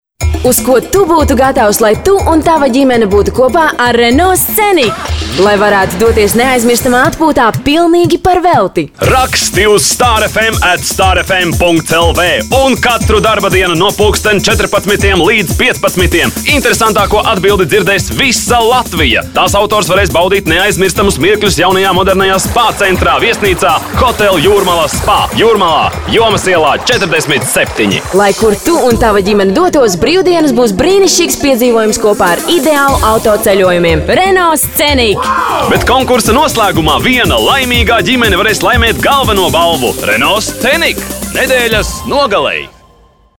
Sprecherin lettisch für TV / Rundfunk / Werbung.
Sprechprobe: Industrie (Muttersprache):
Professionell female voice over artist lettish.